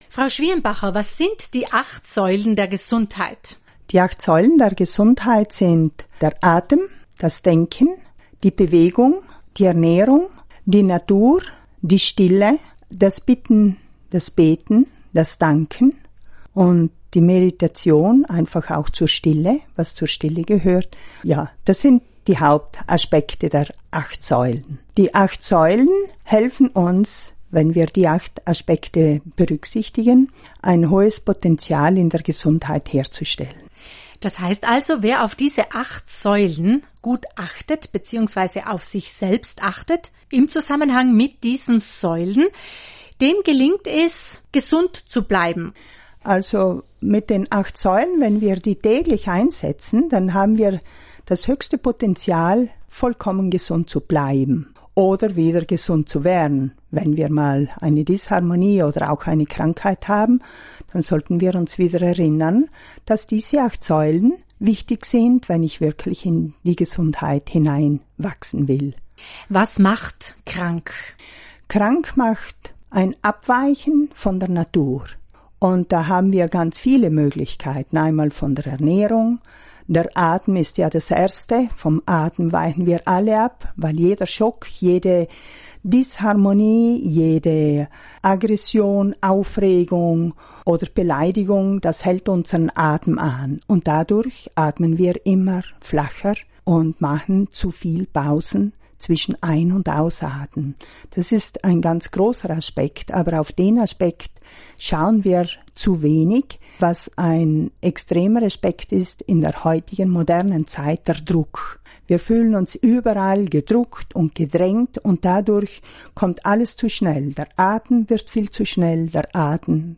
Interview mit Radio Grüne Welle zu den 8 Säulen der Gesundheit
8-Saeulen_Interview-Gruene-Welle.mp3